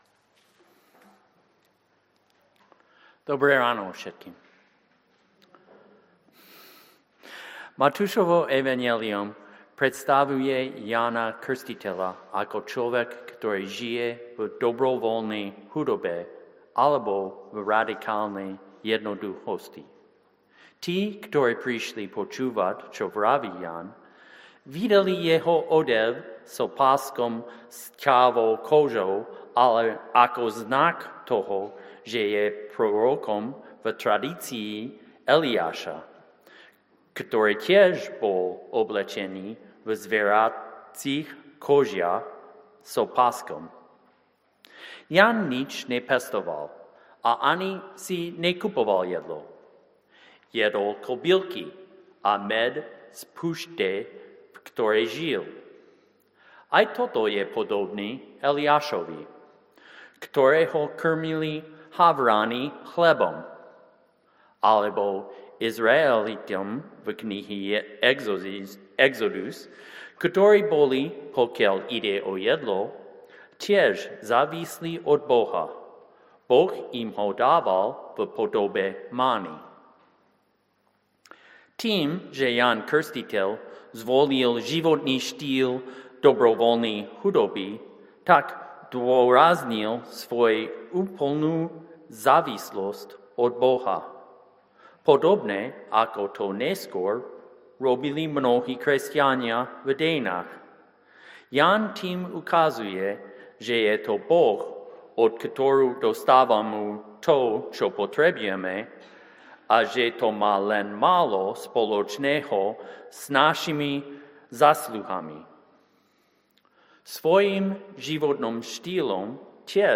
Stránka zboru Cirkvi bratskej v Bratislave - Cukrová 4
- Mich 5:1-6; Lk 2:1-14 Podrobnosti Kázeň Prehliadač nepodporuje prehrávač.